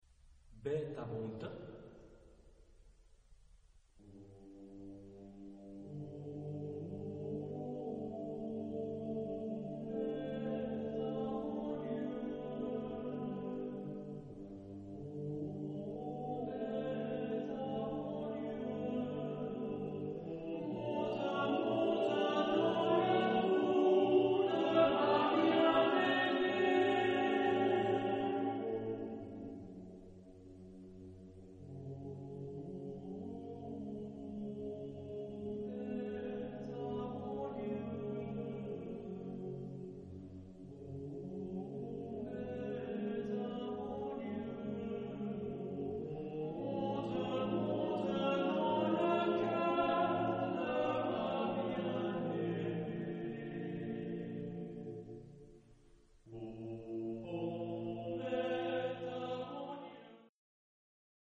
Genre-Style-Form: Choral suite ; Partsong ; Poem ; Secular
Mood of the piece: humorous ; calm
Type of Choir: TTBB  (4 men voices )
Tonality: G major